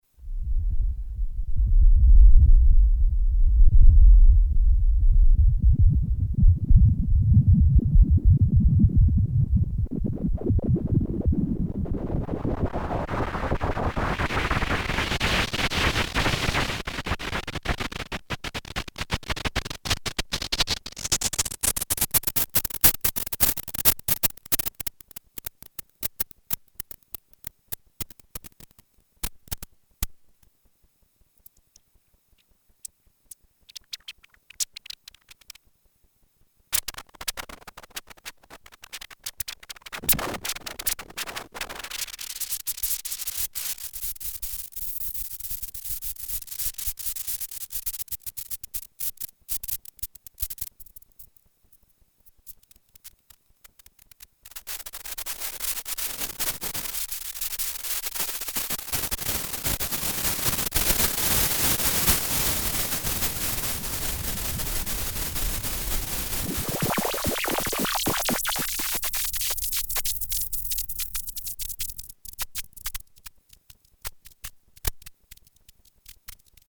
ub F modulating CVs
random sounds such as 'thunder' or
'crackling' can be generated.